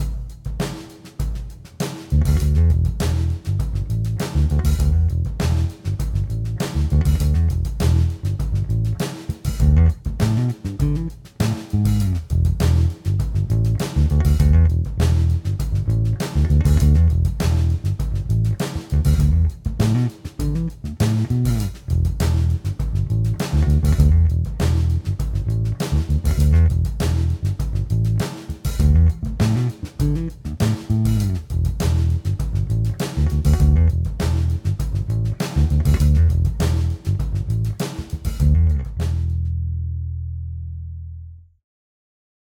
Edit: nahráváno přes VSTčko Ampeg B15R
Mim Precl, ale asi nejlepší, co jsem měl kdy v rukou, úplně nové struny. 2. AV2 Precl, struny rok staré. 3.